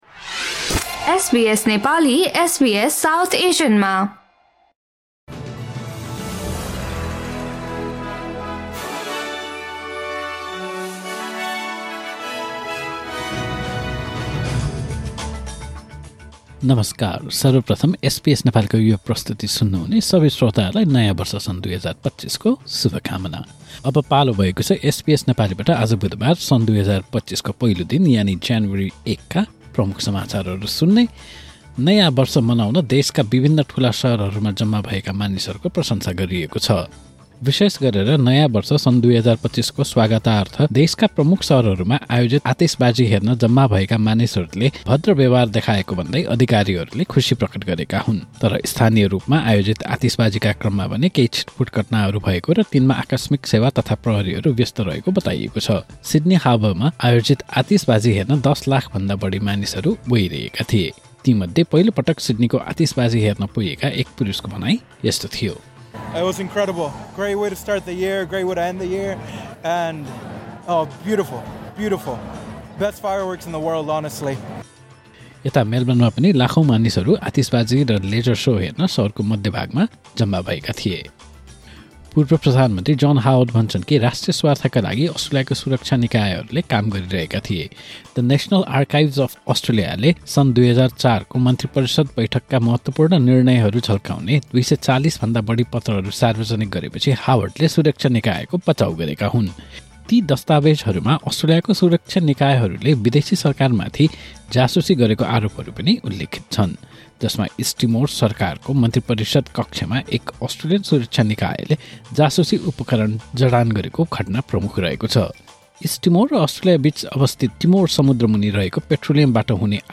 SBS Nepali Australian News Headlines: Wednesday, 1 January 2025